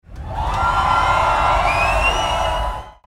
Short-concert-crowd-cheering-sound-effect.mp3